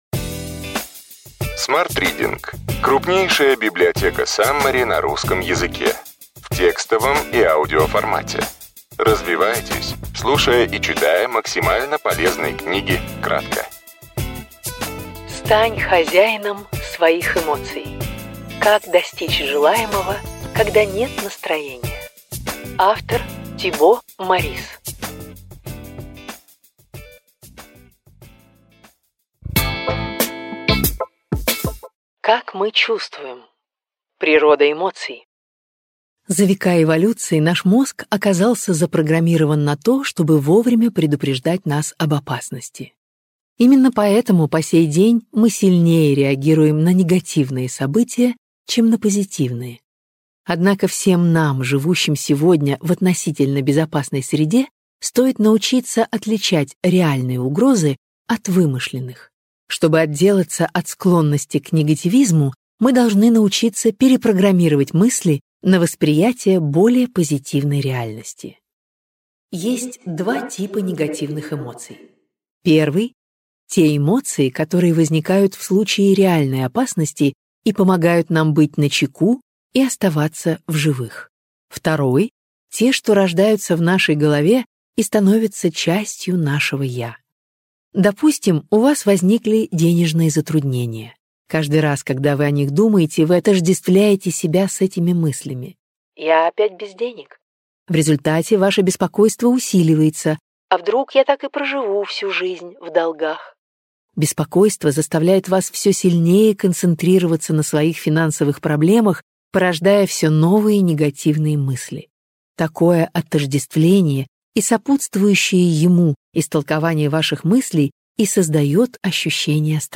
Аудиокнига Ключевые идеи книги: Стань хозяином своих эмоций. Как достичь желаемого, когда нет настроения. Тибо Морисс | Библиотека аудиокниг